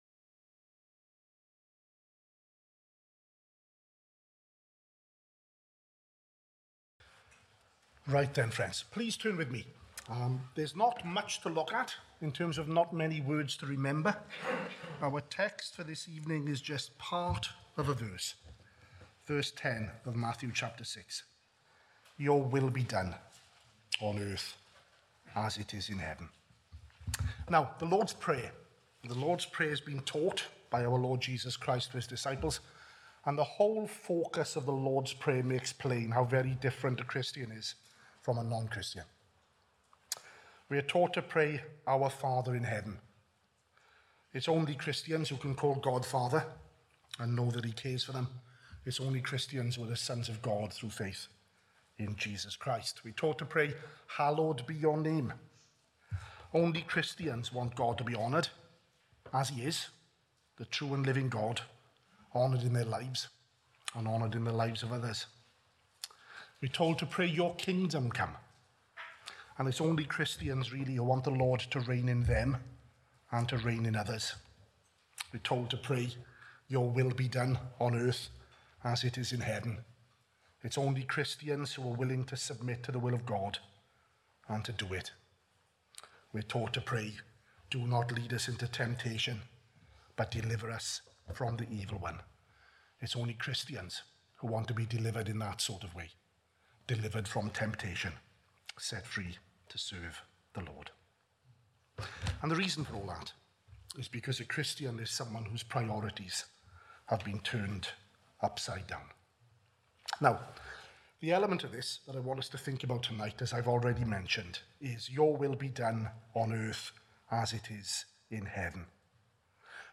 at the evening service